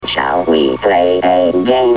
Computer voice from the movie Wargames (1983)